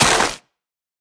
Minecraft Version Minecraft Version 1.21.5 Latest Release | Latest Snapshot 1.21.5 / assets / minecraft / sounds / block / mangrove_roots / break3.ogg Compare With Compare With Latest Release | Latest Snapshot